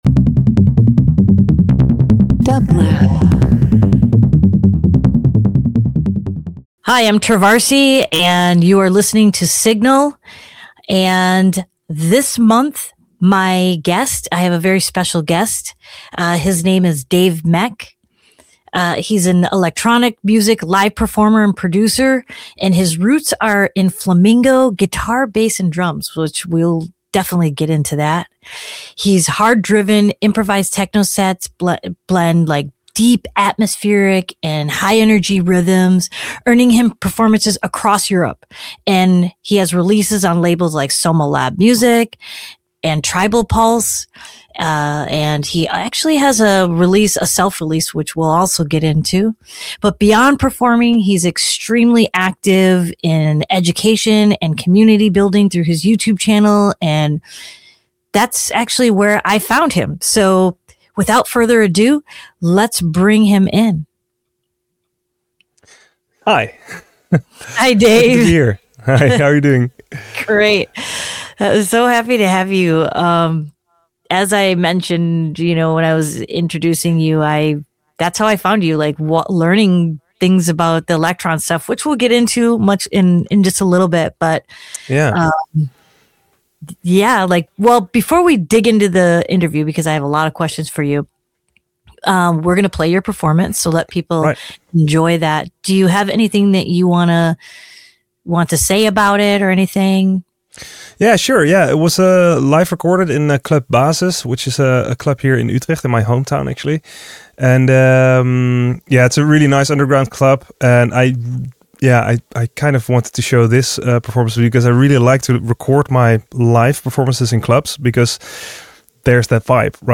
Dance Electronic Techno